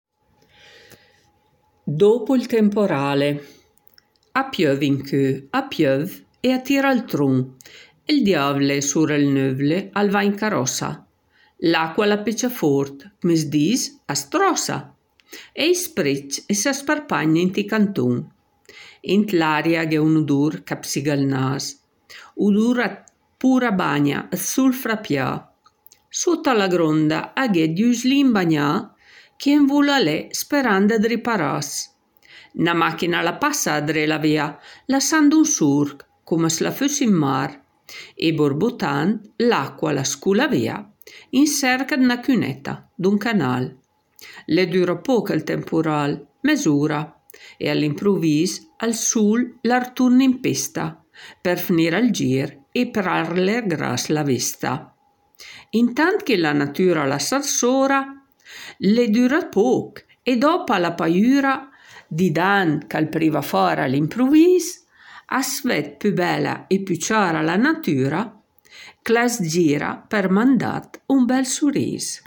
La poesia Dopo il temporale  è letta